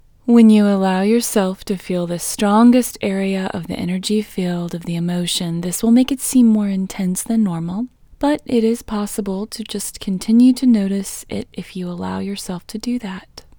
IN Technique First Way – Female English 10